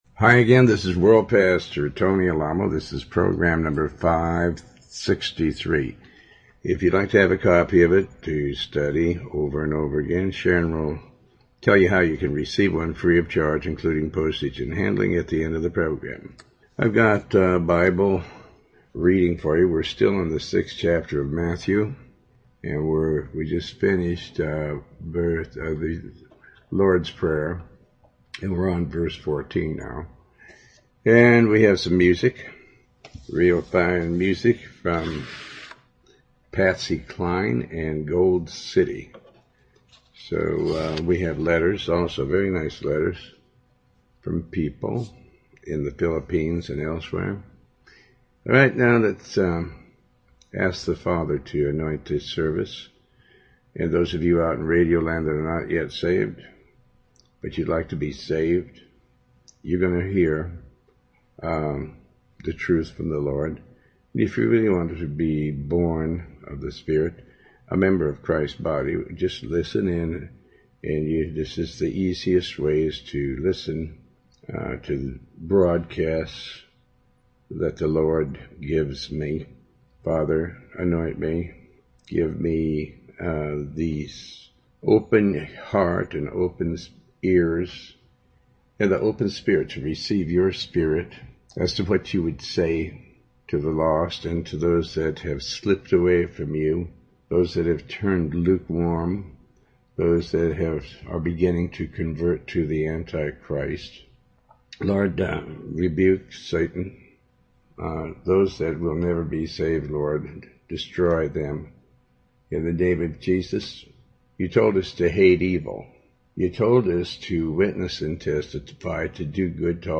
Talk Show Episode
Host Pastor Tony Alamo